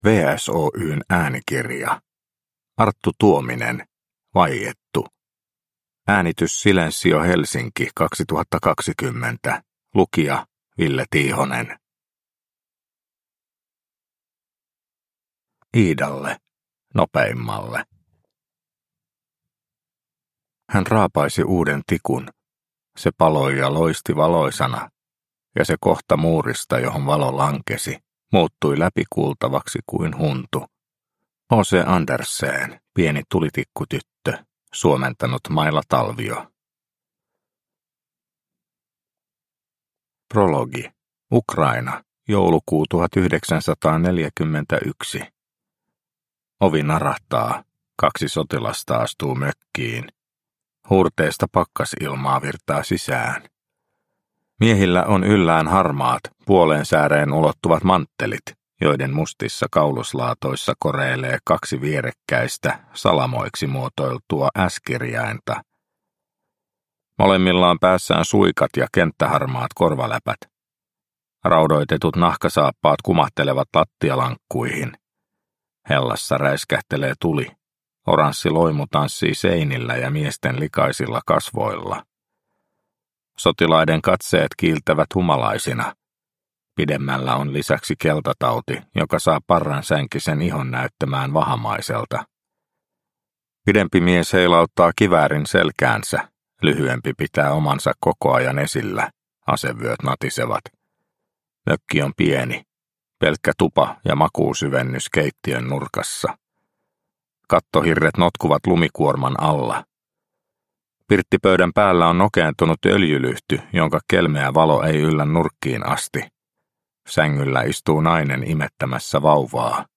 Vaiettu – Ljudbok – Laddas ner